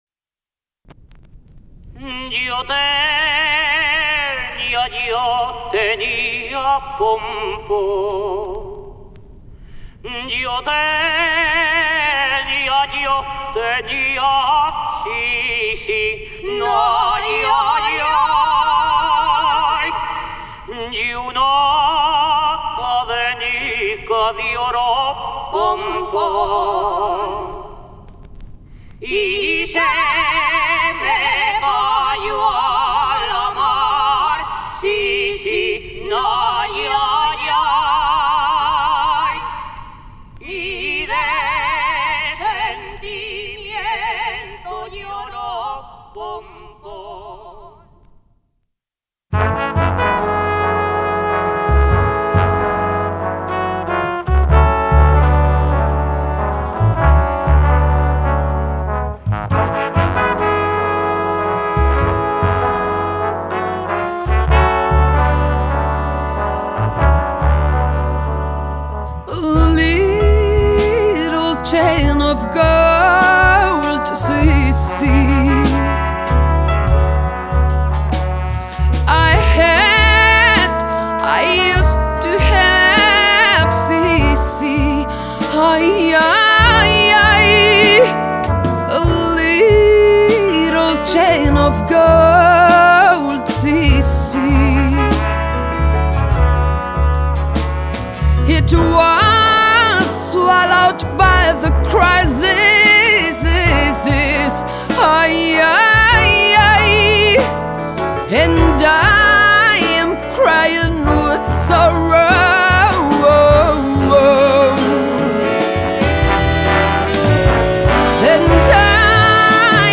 Жанр: Folk.